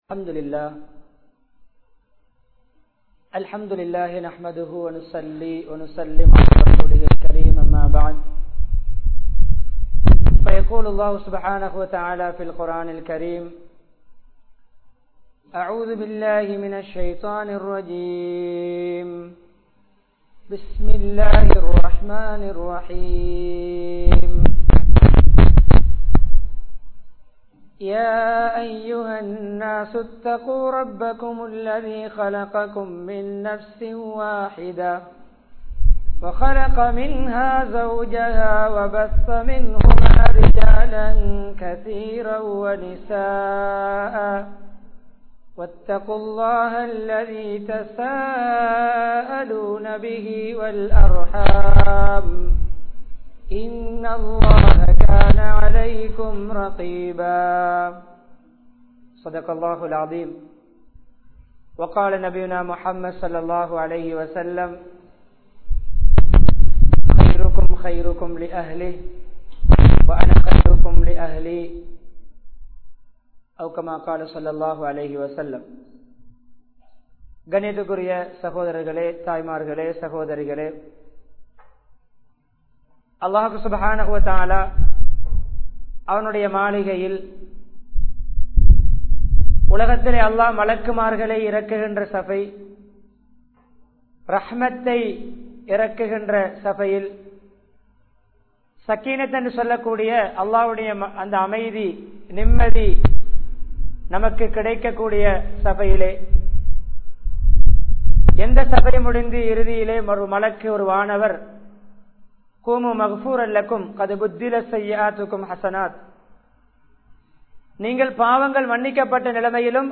Mana Amaithiyai Eatpaduththum Kudumba Vaalkai (மன அமைதியை ஏற்படுத்தும் குடும்ப வாழ்க்கை) | Audio Bayans | All Ceylon Muslim Youth Community | Addalaichenai